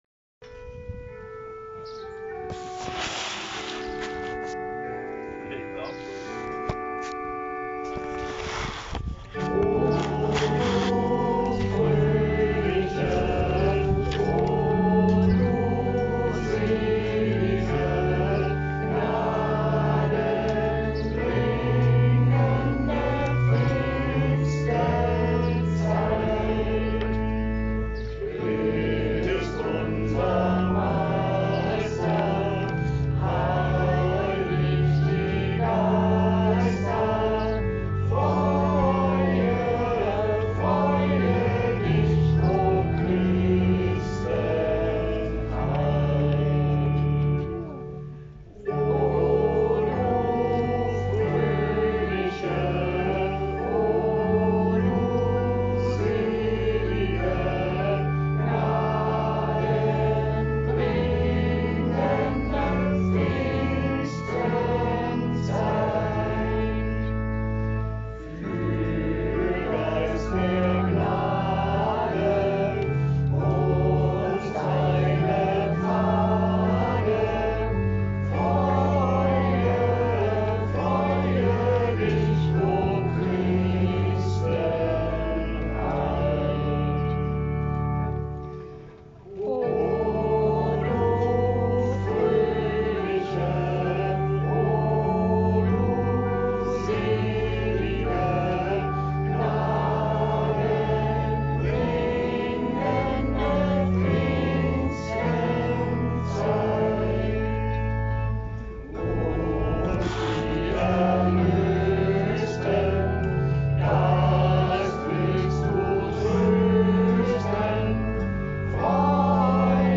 Zum Mühlentag hält er traditionell einen Freiluftgottesdienst ab.
Aber mit einem Weihnachtslied? „Oh Du fröhliche“ kennen die meisten nur aus der Weihnachtszeit.